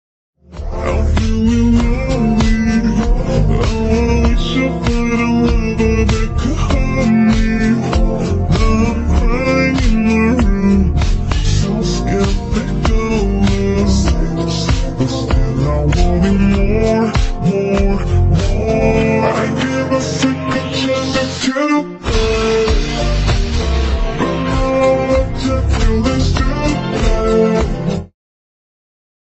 AI Horror